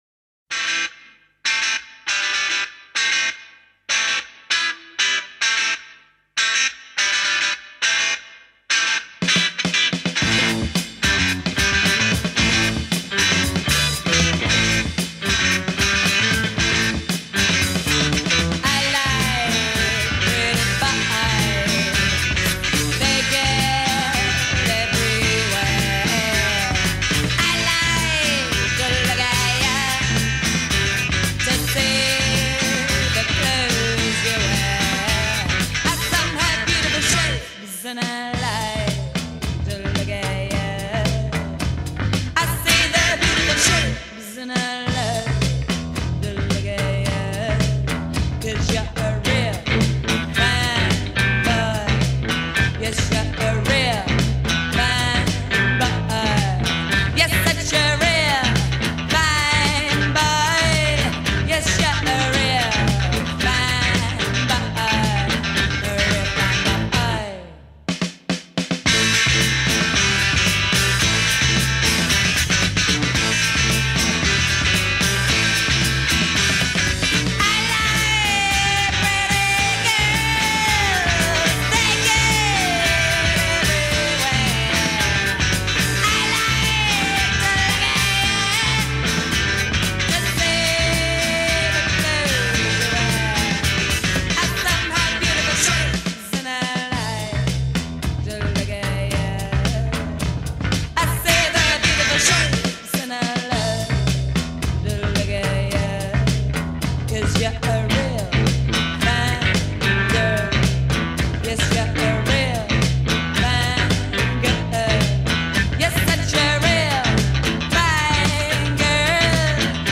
Post-Punk